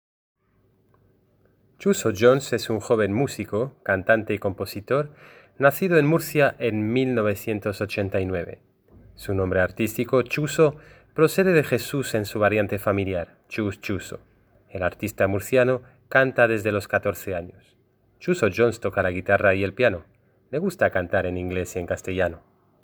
Escucha la lectura del profesor: pincha en  (clique sur) el azul (primer texto) o pincha en el verde (segundo texto)